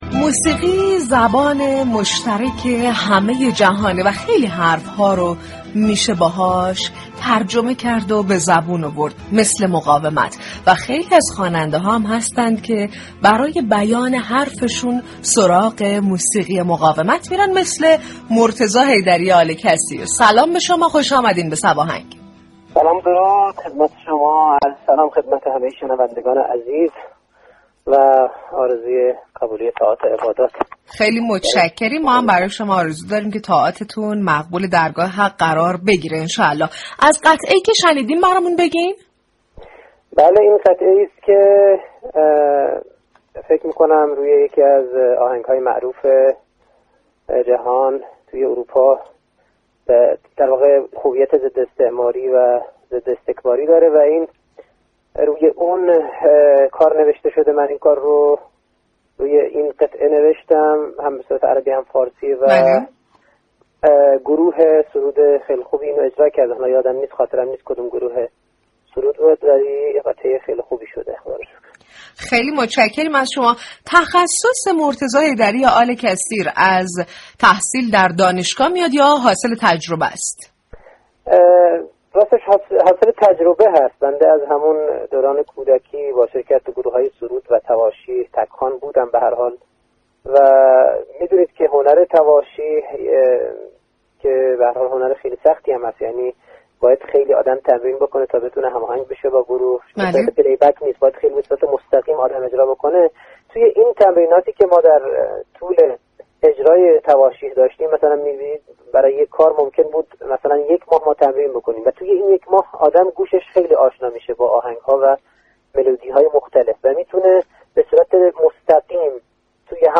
به گزارش روابط عمومی رادیو صبا، «صباهنگ» برنامه موسیقی محور رادیو صبا است كه با پخش ترانه های درخواستی، فضای شادی را برای مخاطبان این شبكه ایجاد می كند.